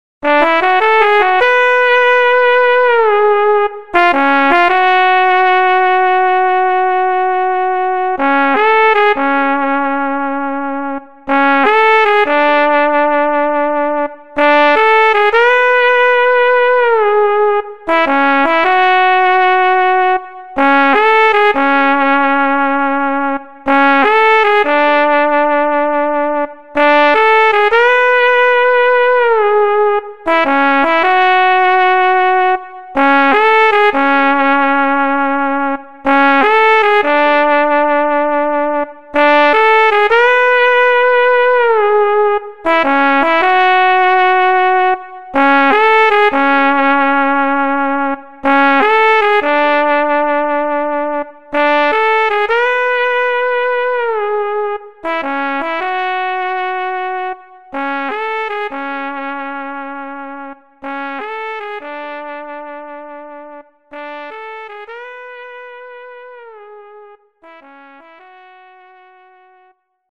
HALion6 : trombone
Trombone Vibrato Medium